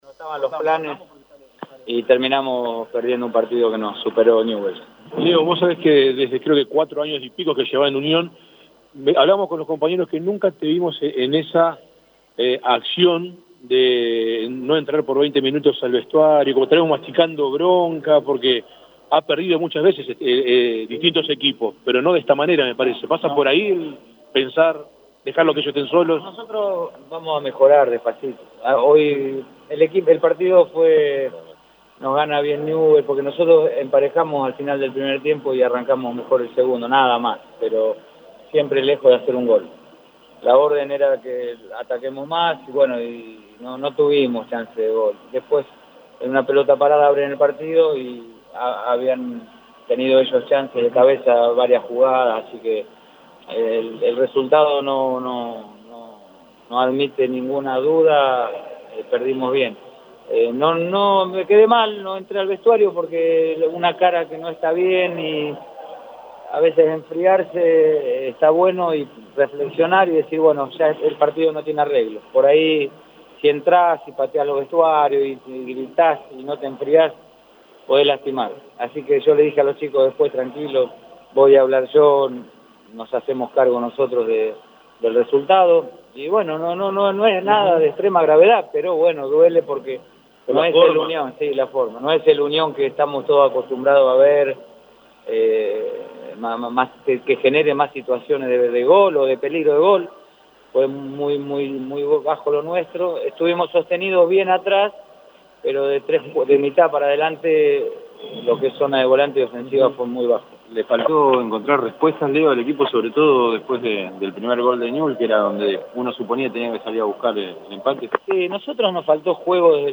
Escucha la palabra del entrenador Tatengue:
MADELON-CONFERENCIA.mp3